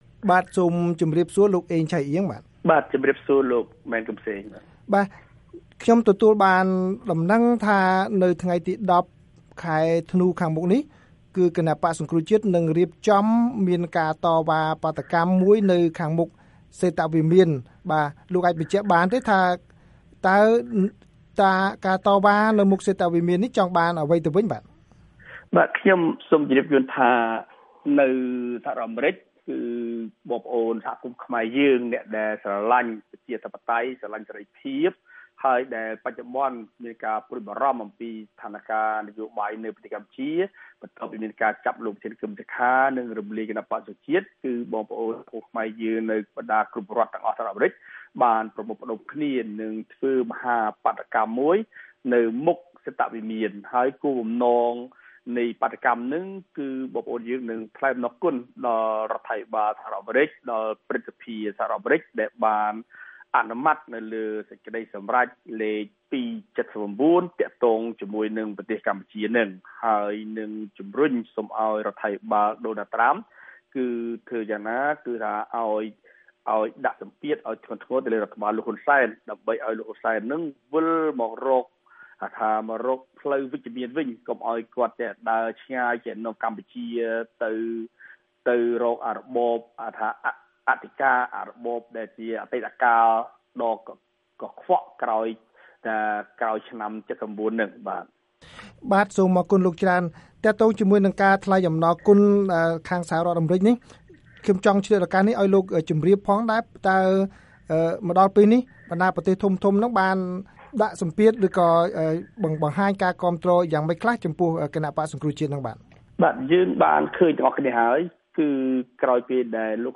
បទសម្ភាសន៍ VOA៖ បក្សសង្គ្រោះជាតិគ្រោងបាតុកម្មនៅសហរដ្ឋអាមេរិក បារាំង និងអូស្ត្រាលី